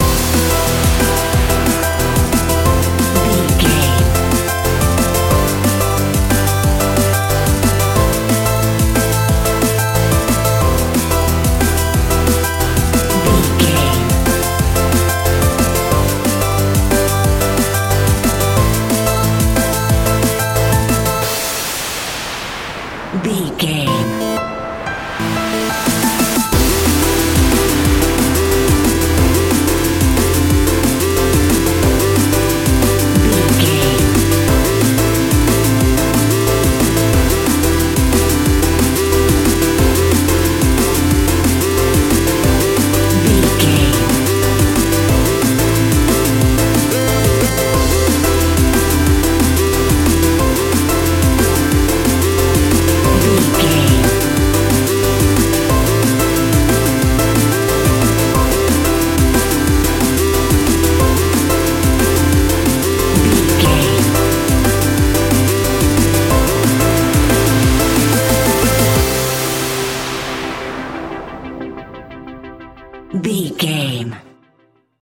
Ionian/Major
Fast
groovy
uplifting
futuristic
driving
energetic
repetitive
drum machine
synthesiser
break beat
electronic
sub bass
synth leads
synth bass